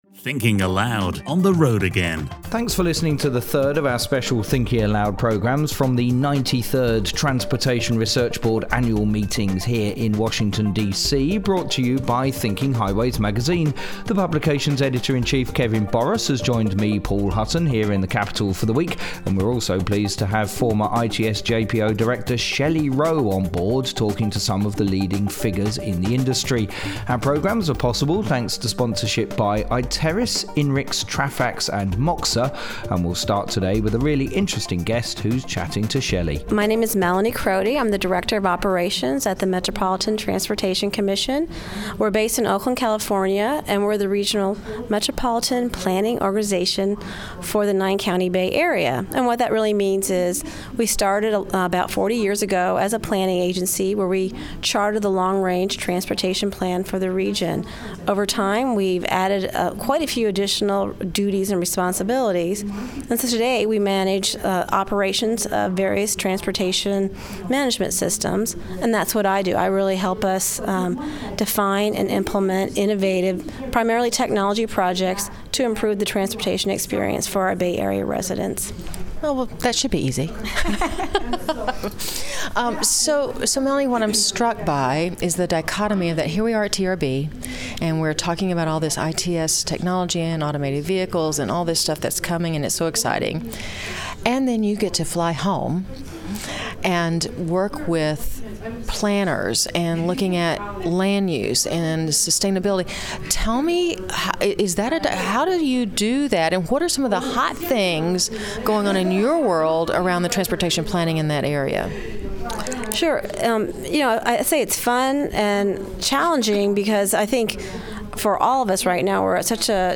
thinking-aloud-live-from-trb-show-3.mp3